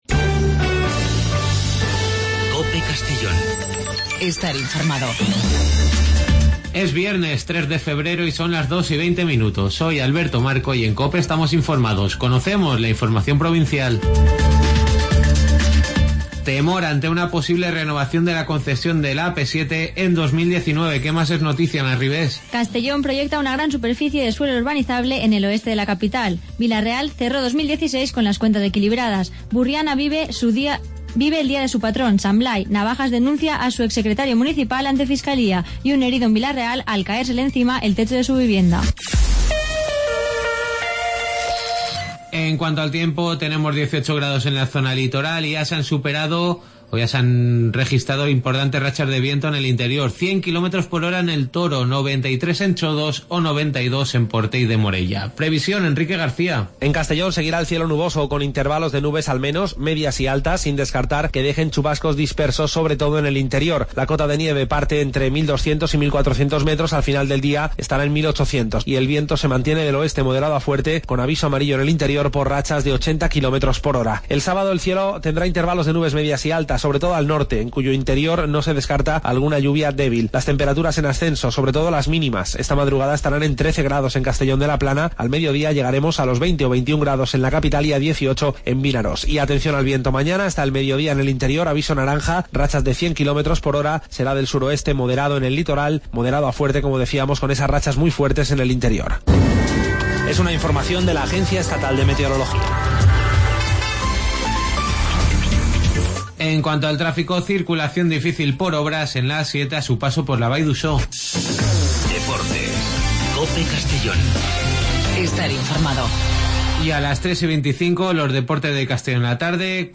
AUDIO: Las noticias del día de 14:20 a 14:30 en Informativo Mediodía COPE en Castellón.